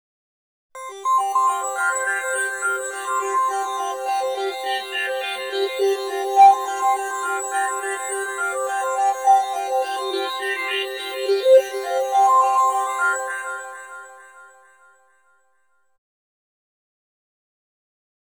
04_specialsfx_13_SQ.wav